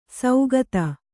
♪ saugata